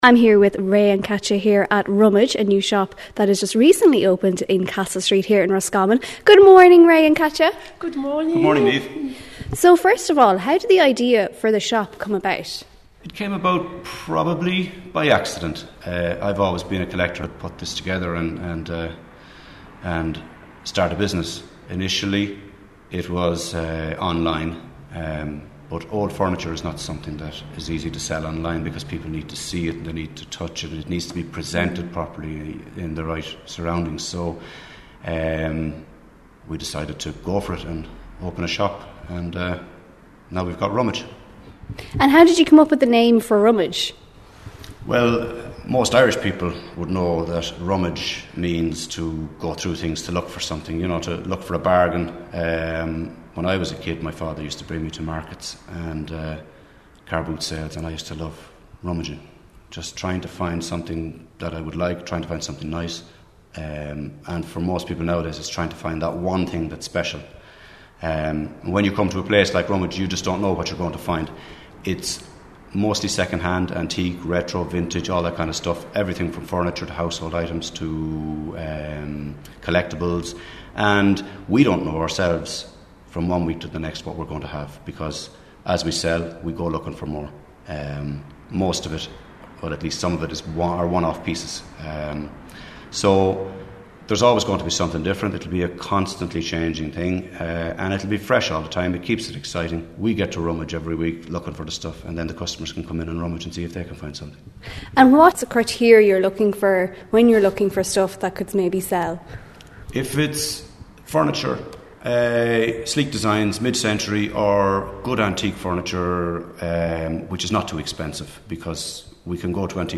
Roscommon Shop Rummage Interview - RosFM 94.6